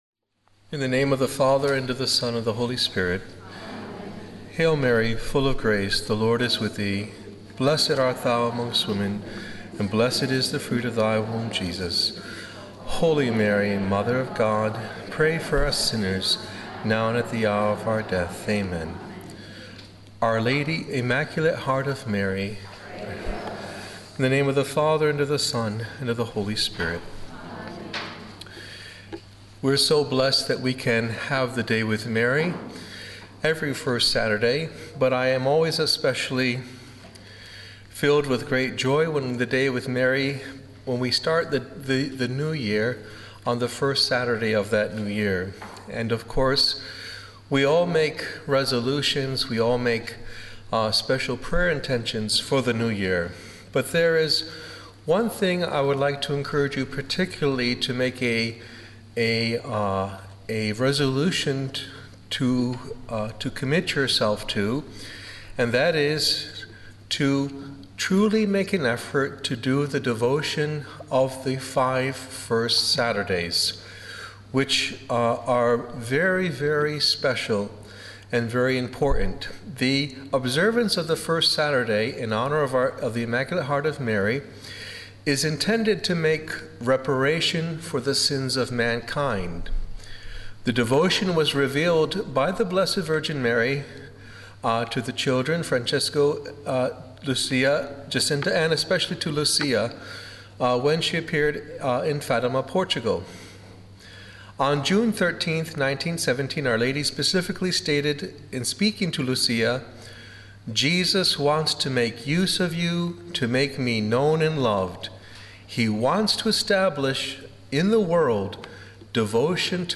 held at St. Lawrence and Mary Immaculate Church in Balcatta, Western Australia